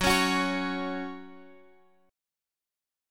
F#5 chord {x 9 11 11 x 9} chord